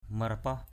/mə-ra-pɔh/